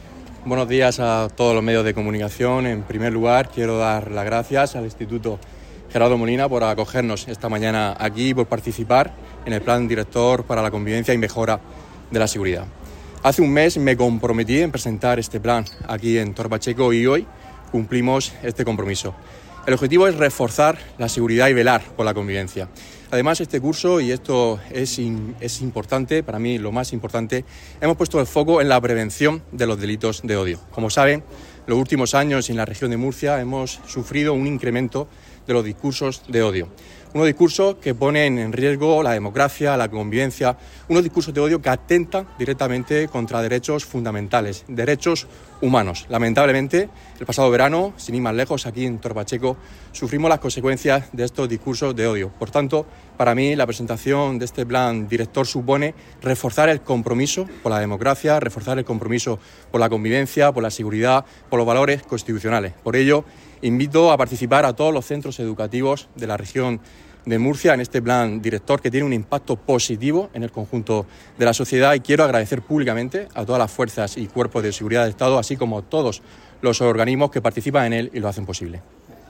La presentacion tuvo lugar en el IES Gerardo Molina
Declaraciones de Francisco Lucas